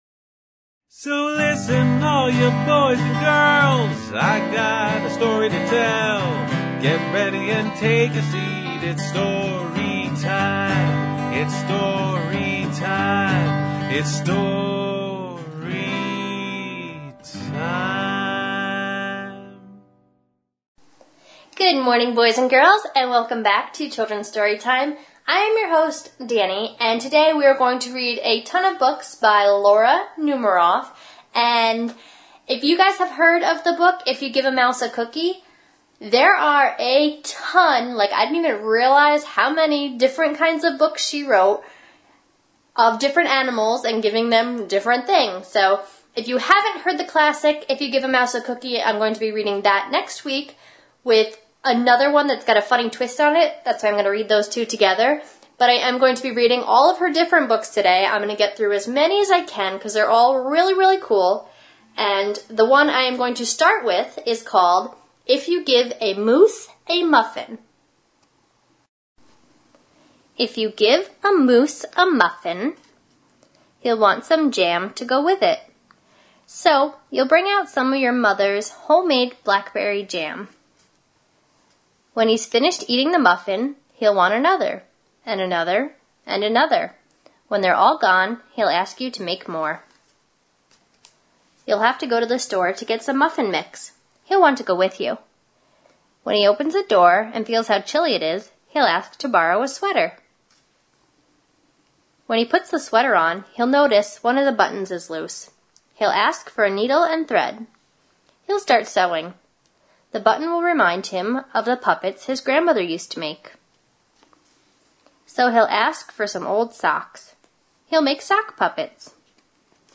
8:30 am A radio program of a collection of stories, jokes,...